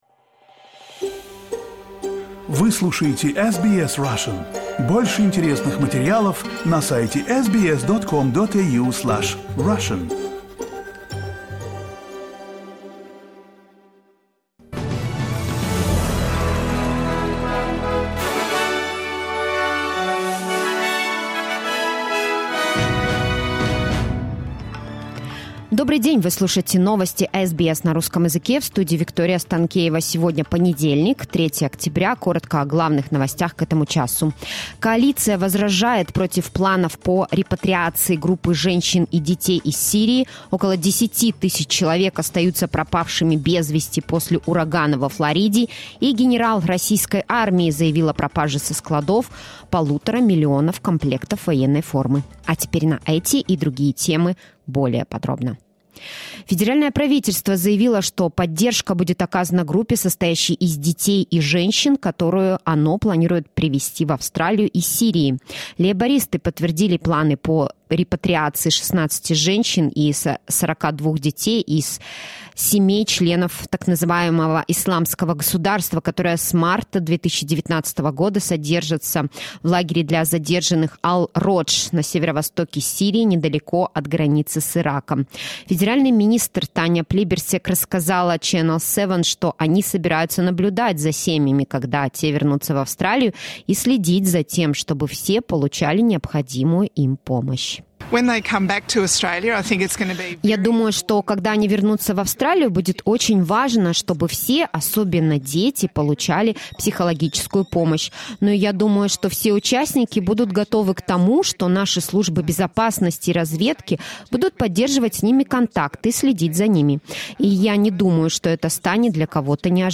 SBS news in Russian — 03.10.22